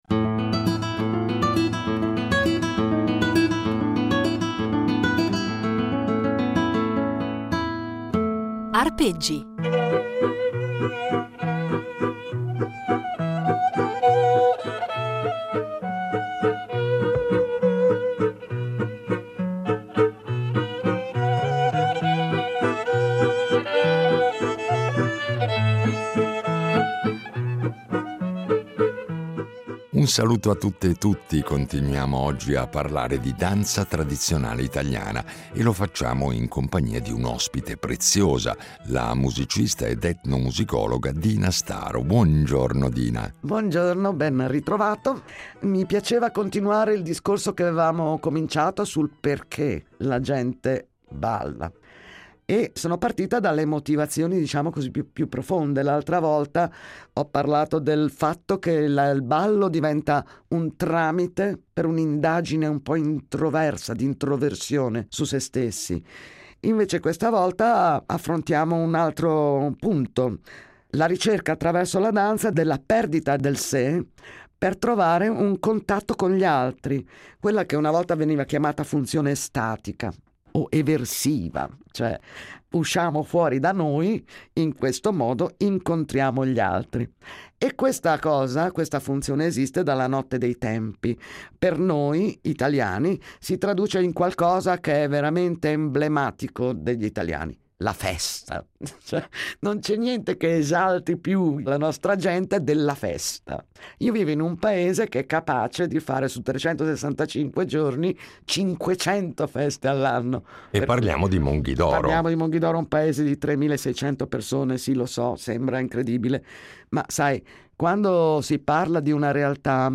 Un itinerario sonoro ricco di materiale inedito, registrato sul campo e negli anni da lei stessa